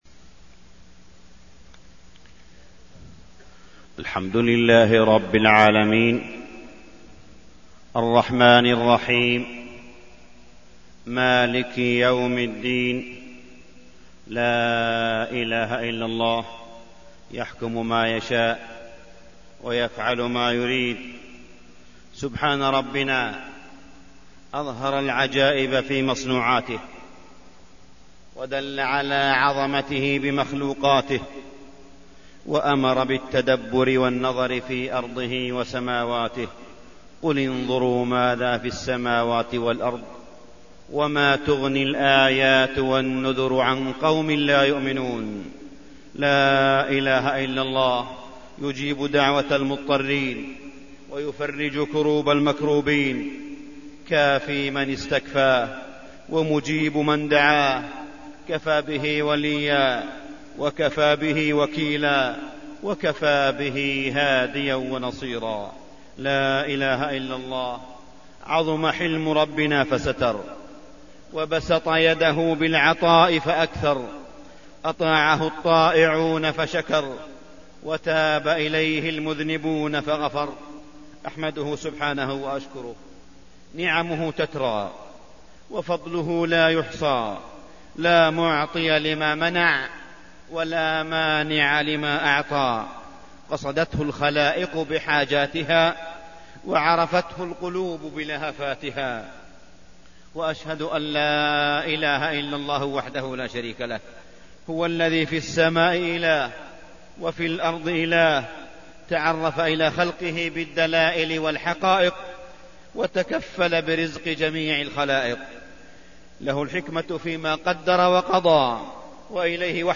تاريخ النشر ٢٠ شوال ١٤١٧ هـ المكان: المسجد الحرام الشيخ: معالي الشيخ أ.د. صالح بن عبدالله بن حميد معالي الشيخ أ.د. صالح بن عبدالله بن حميد هلاك الأمم الغابرة